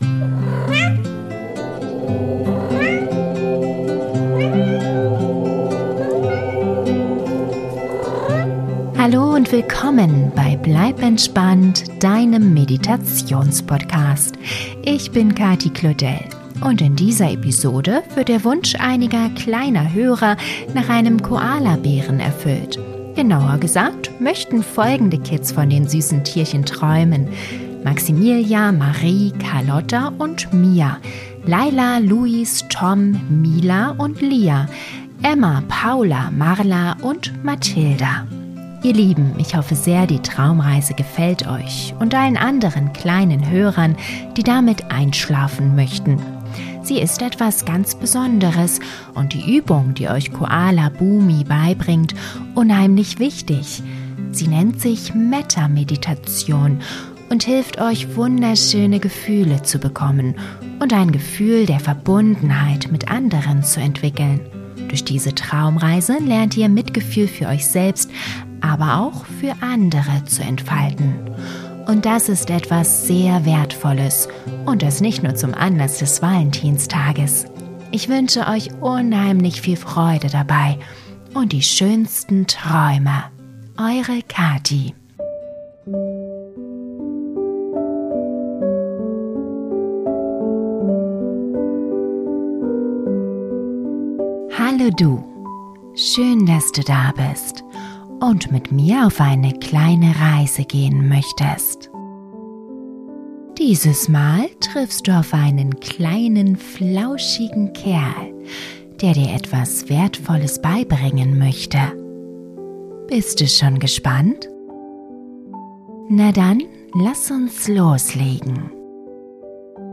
Diese Traumreise für Kinder zum Einschlafen ist etwas ganz Besonderes. Dein Kind lernt in der Geschichte Koala Bumi kennen und der entspannte kleine Kerl weiß so einiges über Mitgefühl und Selbstliebe.